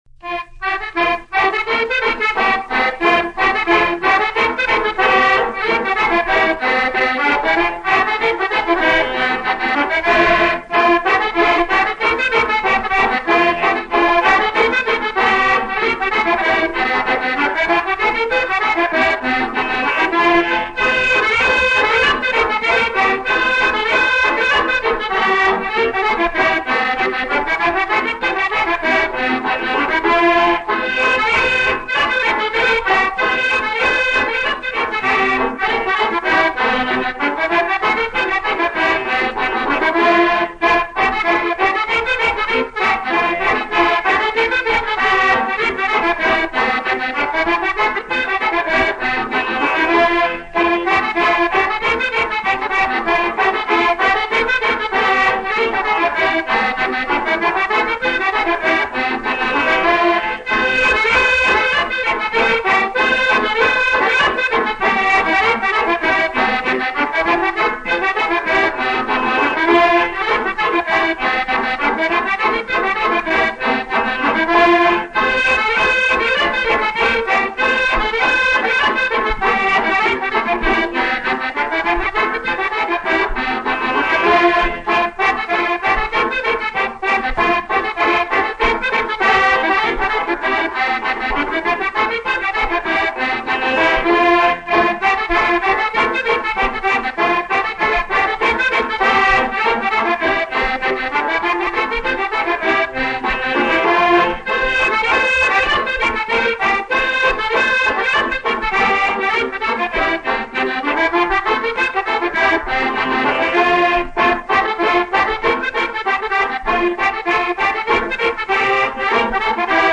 Scottish